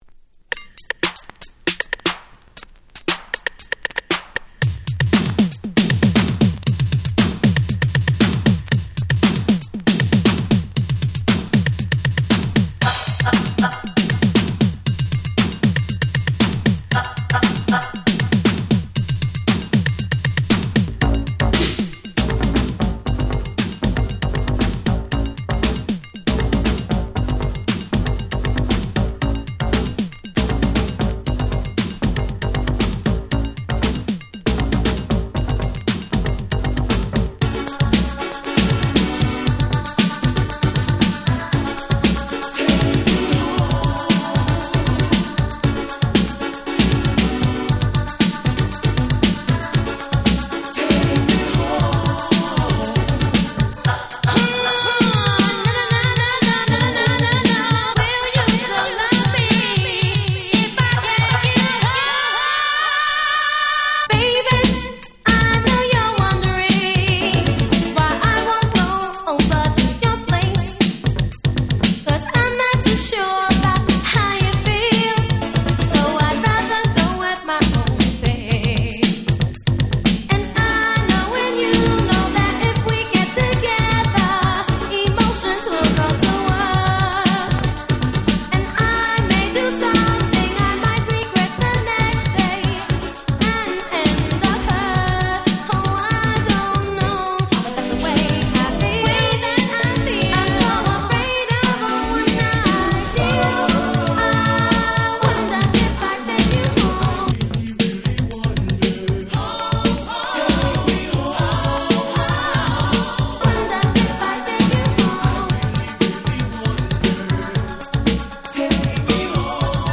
84' Super Hit Disco !!